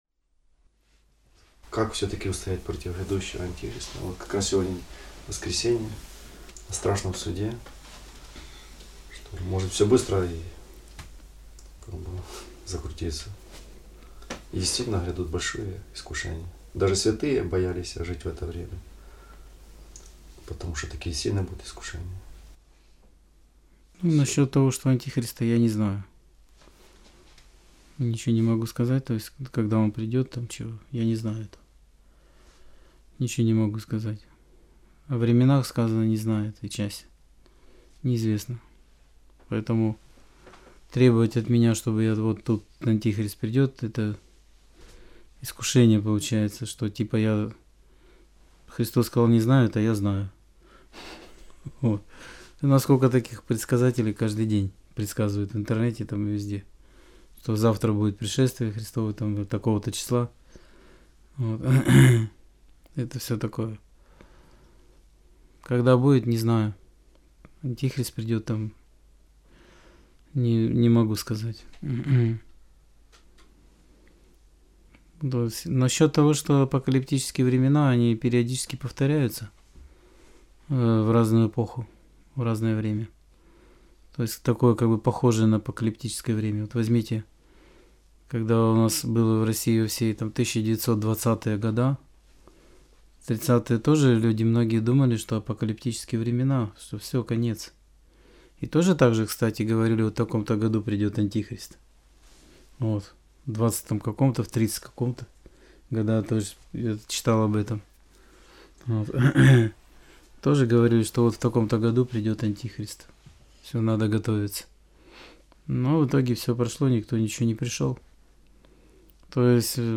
Аудио-проповедь 07.03.2021